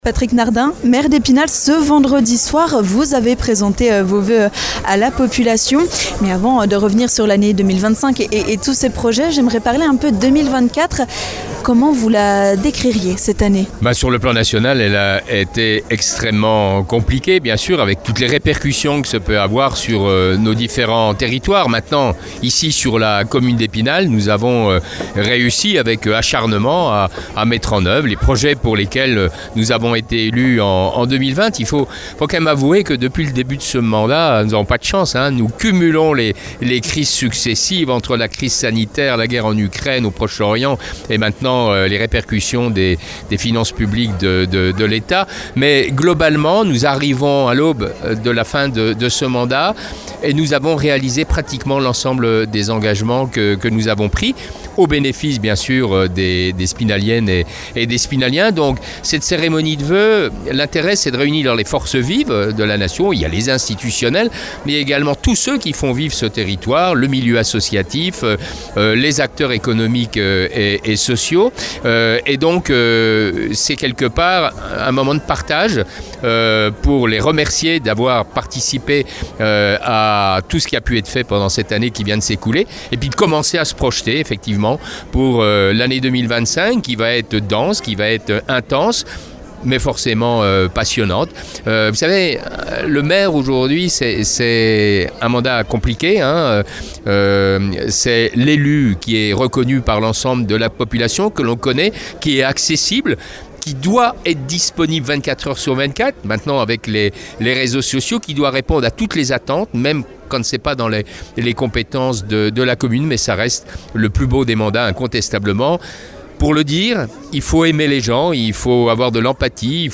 Et pour 2025, la municipalité s'attend à tout et se prépare au futur budget du pays. Nous sommes allés à la rencontre de Patrick Nardin pour en savoir plus sur ces craintes concernant ce vote du budget et comment la Ville devra s'adapter.